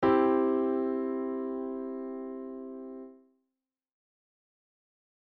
When you play a few notes together at the same time, this is called playing a chord.
C chord.mp3